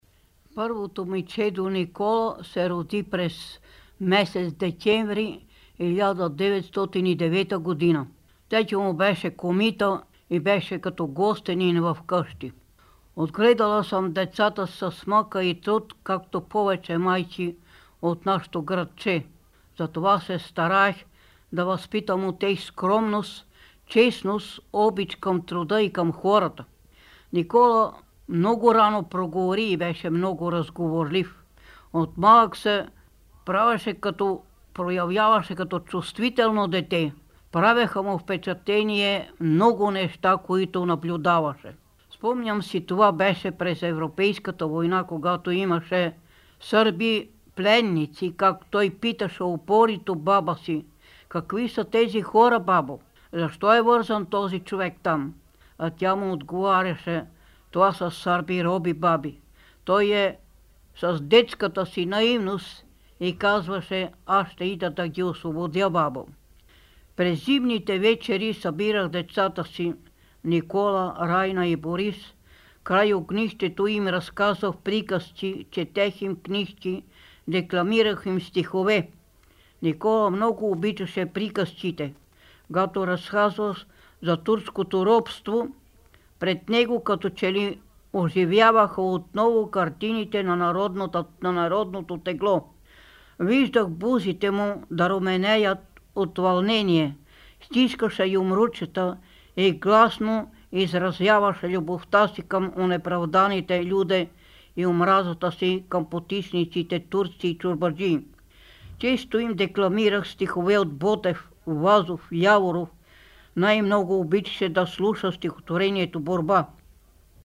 в интервю за Българското национално радио (БНР)